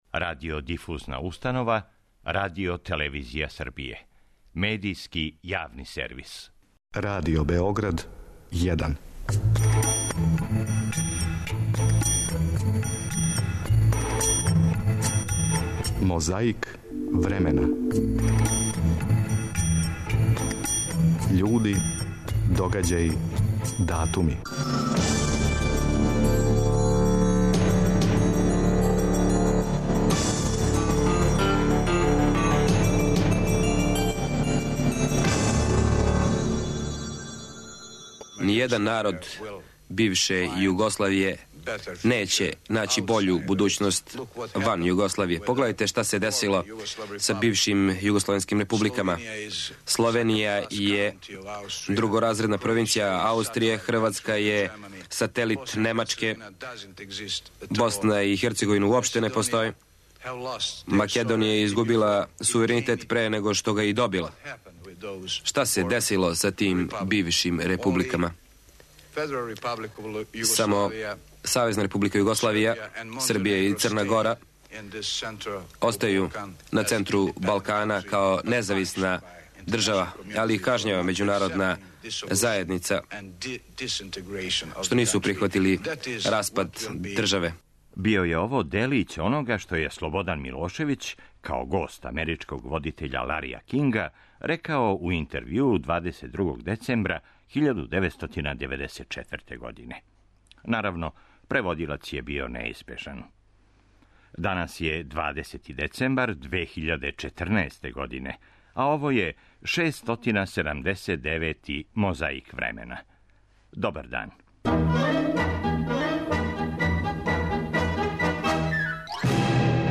Чувени амрички телевизијски и радио водитељ Лари Кинг, угостио је 22. децембра 1994. године Слободана Милошевића. Наравно, преводилац је био неизбежан. На почетку емисије чућете делић онога што је Милошевић рекао у интервјуу.